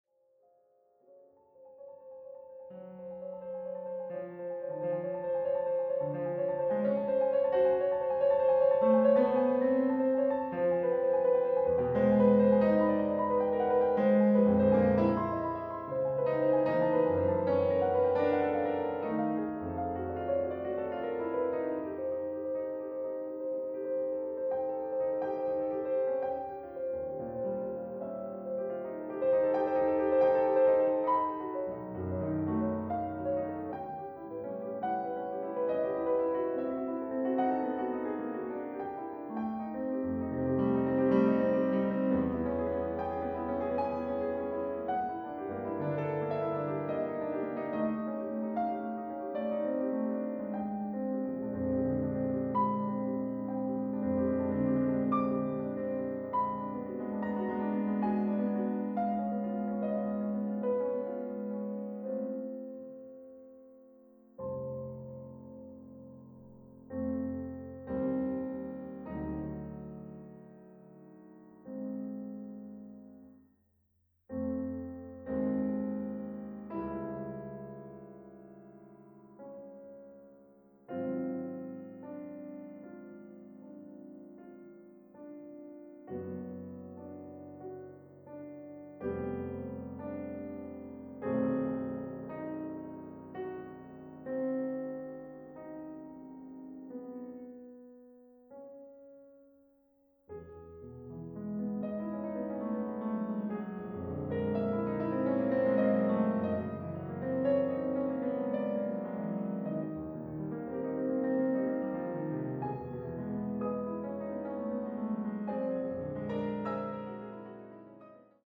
piano
Australian, Classical, Keyboard